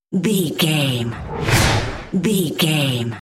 Whoosh fast trailer
Sound Effects
Fast paced
Fast
futuristic
intense
whoosh